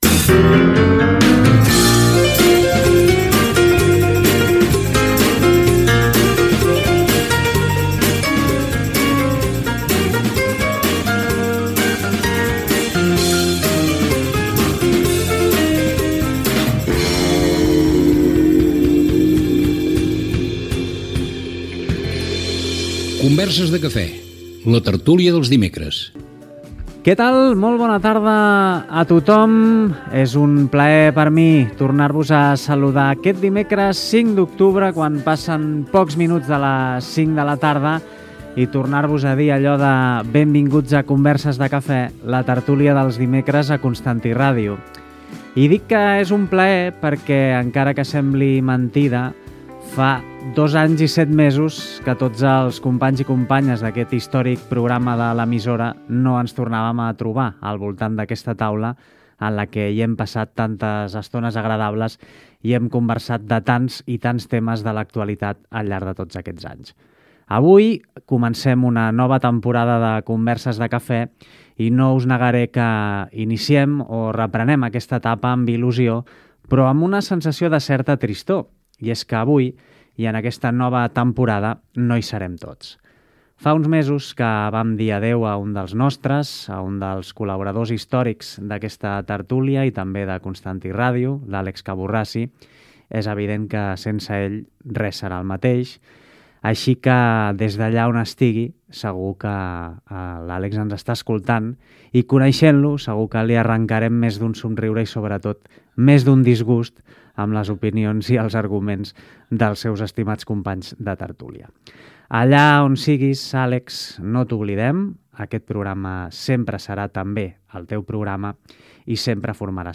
Pública municipal
Tertúlia dels dimecres. Recuperació de la tertúlia presencial després de dos anys i set mesos, després de no fer-la per efectes de la pandèmia de la Covid-19. Recordatori d'un company que havia mort.